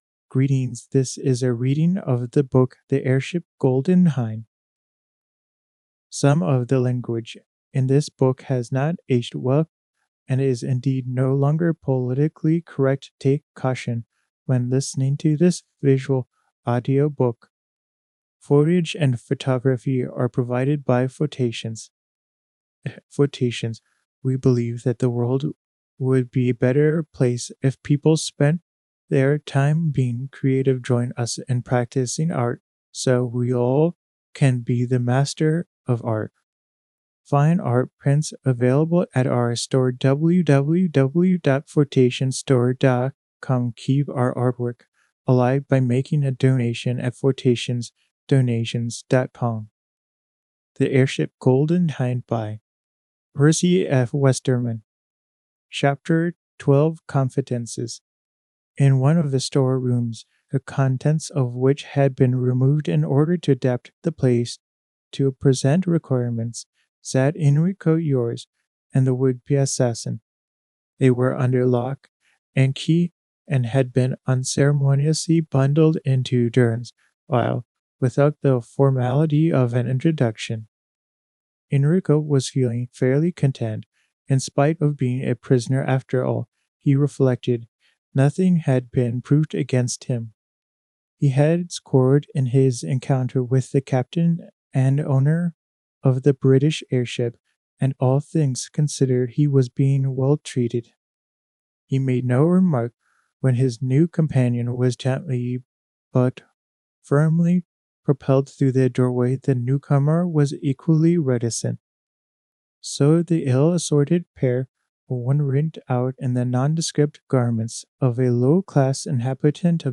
Percy F. Westerman Visual Audio Books from Photations
Closed Caption Read along of The Airship Golden Hind by Percy F. Westerman